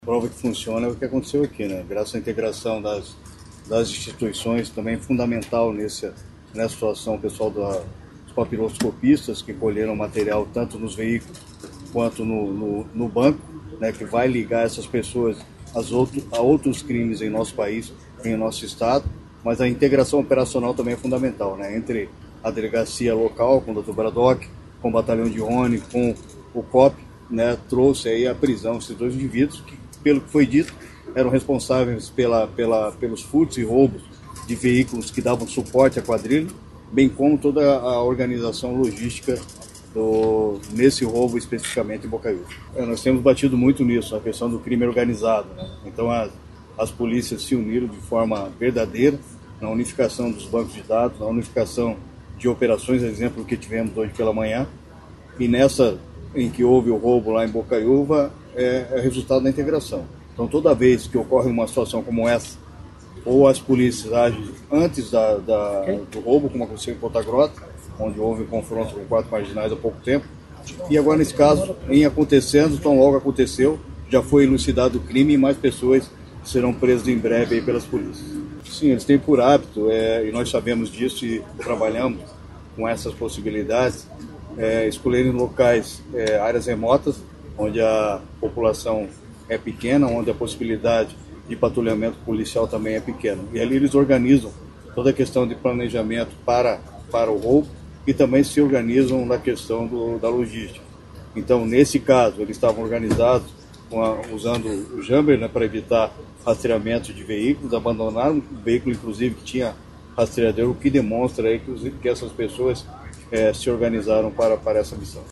Sonora do secretário Estadual da Segurança Pública, Hudson Teixeira, sobre a prisão de suspeitos de tentativa a roubo de um banco em Bocaiúva do Sul